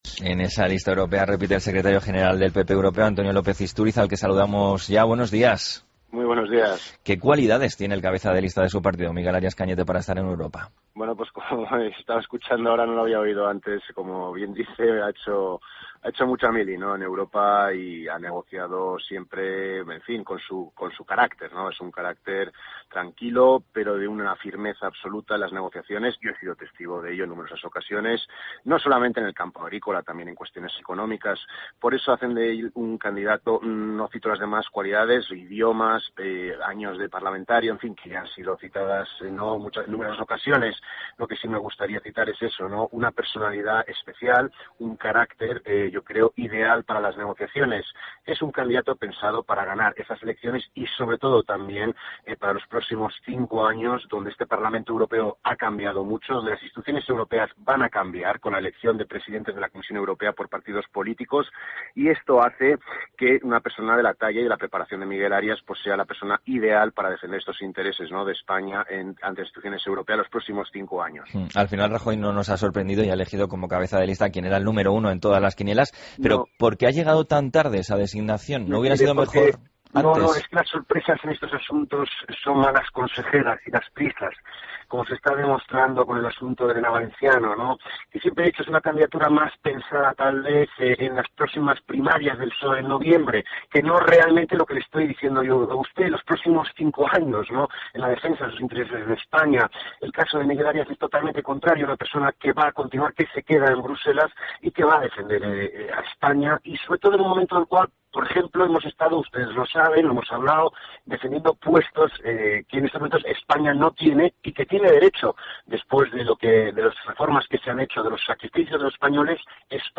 Entrevista a López-Isturiz en La Mañana de COPE